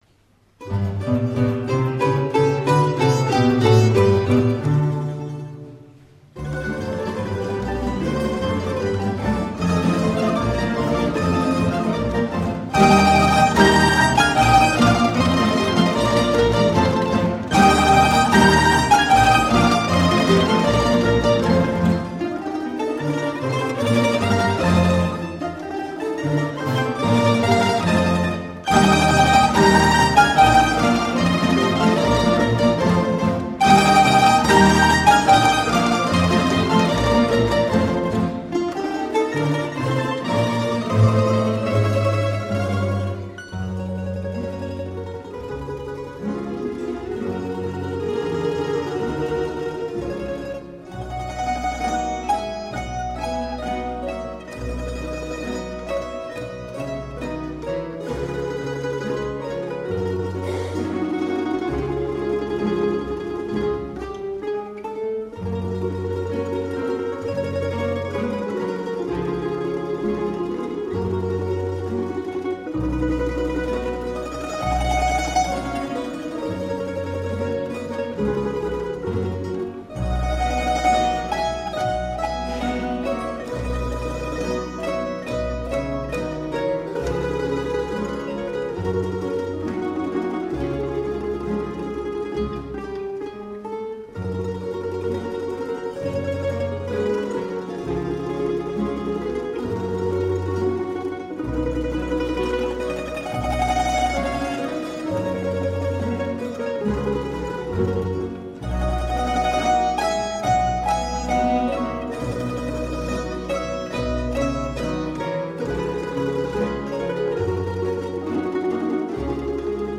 2006 HNK Varaždin
5 tracks - Live
Tamb